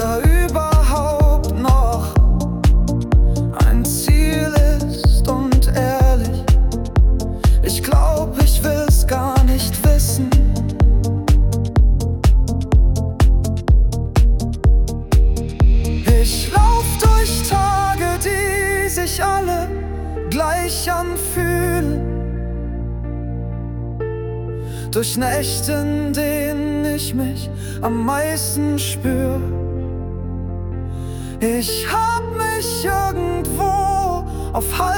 Жанр: Поп музыка / Альтернатива